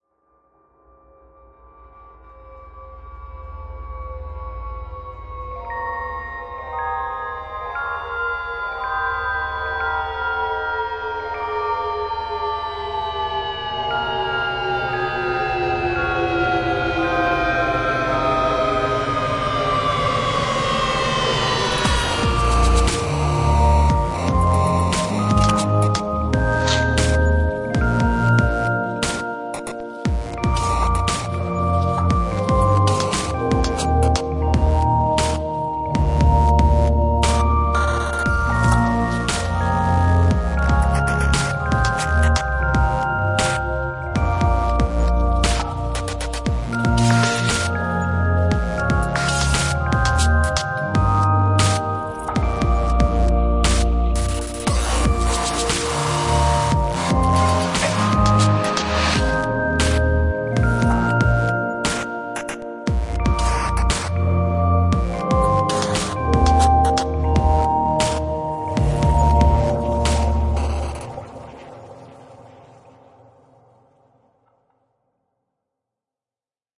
描述：太空中的中提琴。
标签： 未来派 电子 旋律 中提琴 空间 科幻
声道立体声